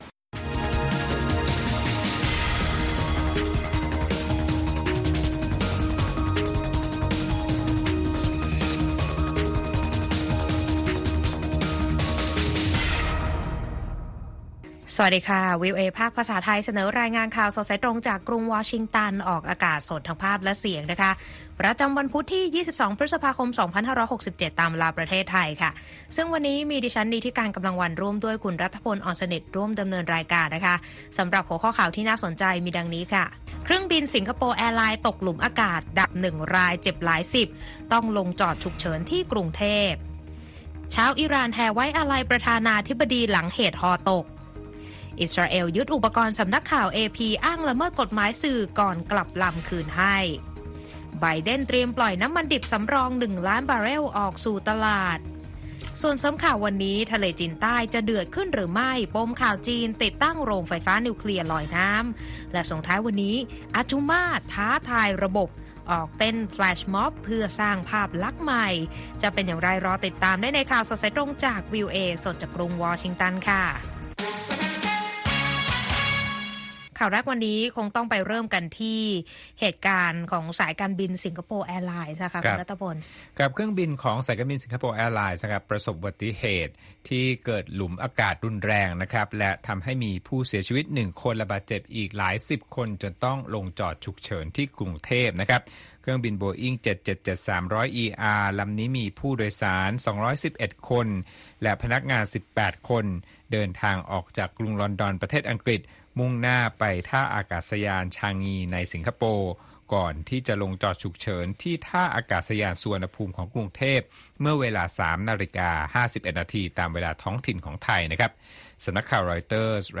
ข่าวสดสายตรงจากวีโอเอไทย 8:30–9:00 น. วันพุธที่ 22 พฤษภาคม 2567